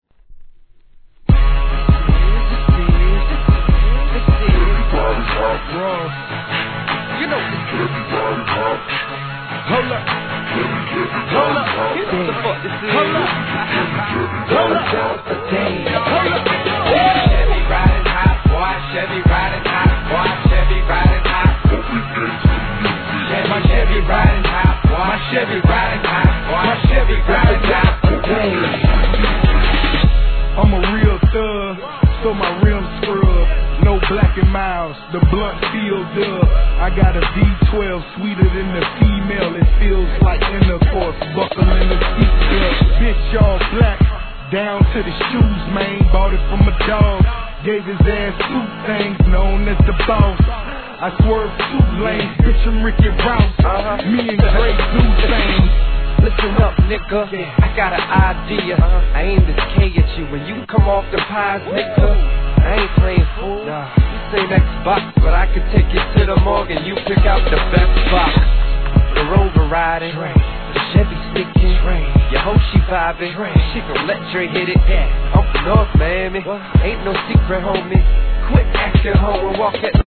HIP HOP/R&B
スクリュードな典型的なDIRTY SOUTHサウンドで